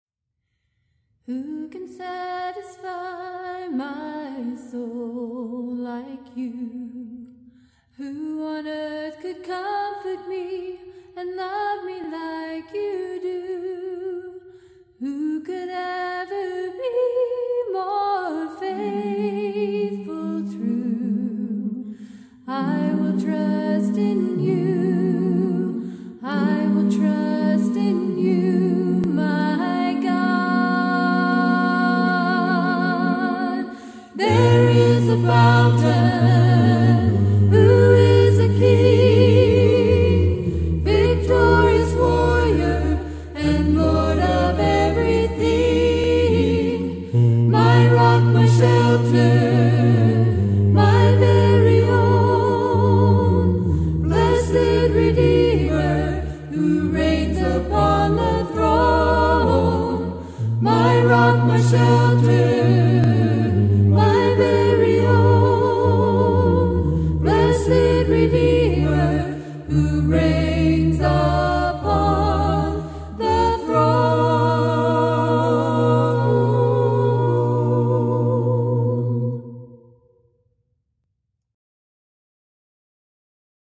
Hino é poema, cântico em honra de Deus.